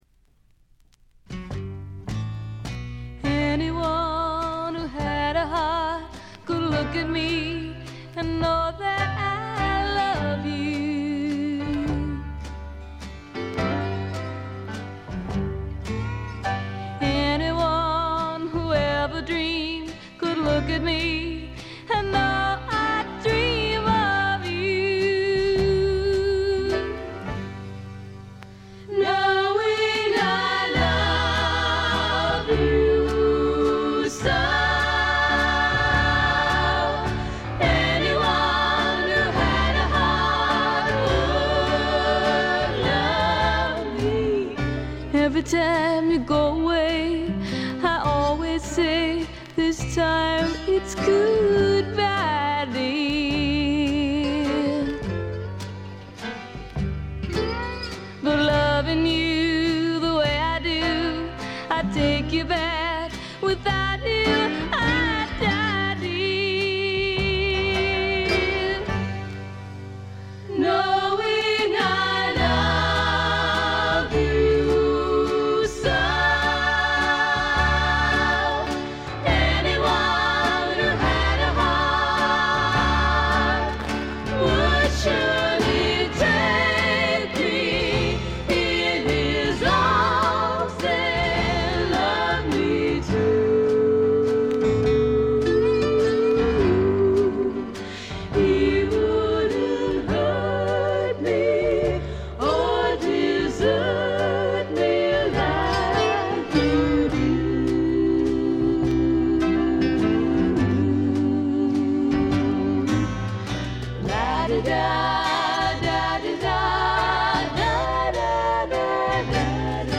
3人娘のヴォーカル・ユニット
試聴曲は現品からの取り込み音源です。
acoustic bottleneck guitar